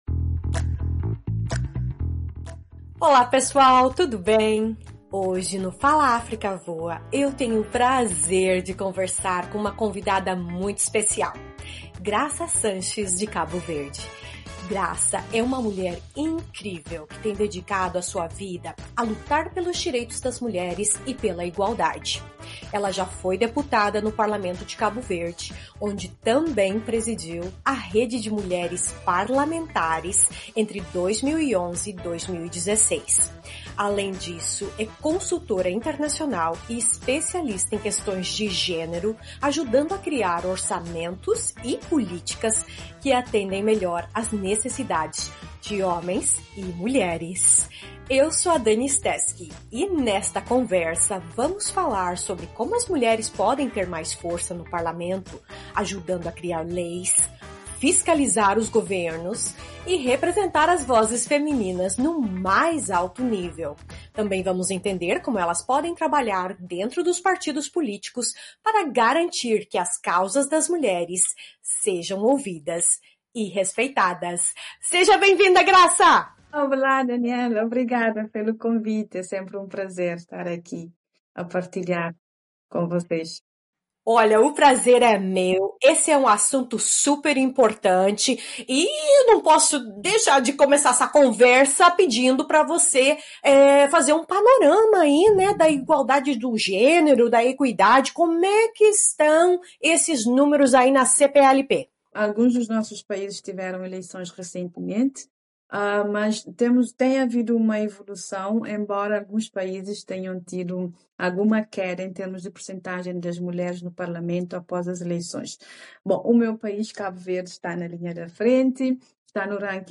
A luta pela igualdade de género e pela maior participação das mulheres na política continua a ser um desafio na Comunidade dos Países de Língua Portuguesa (CPLP). Graça Sanches, ex-deputada cabo-verdiana e especialista em questões de género, destacou em entrevista ao Fala África que, embora haja...